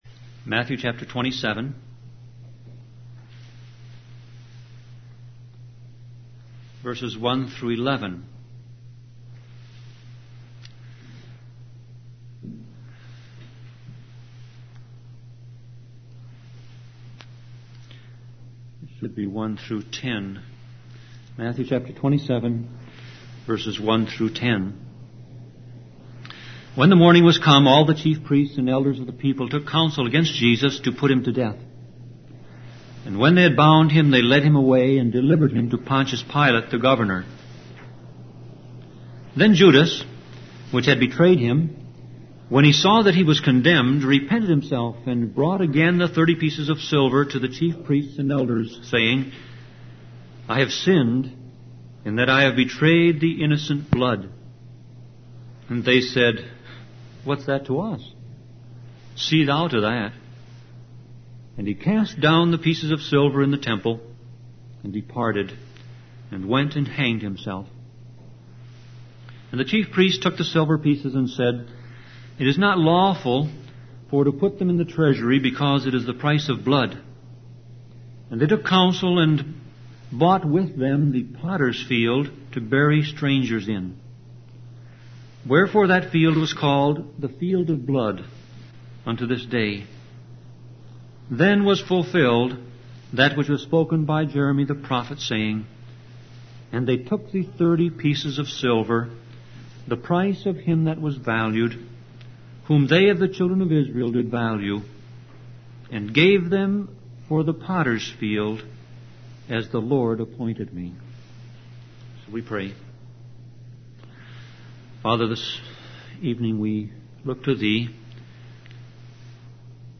Sermon Audio Passage: Matthew 27:1-11 Service Type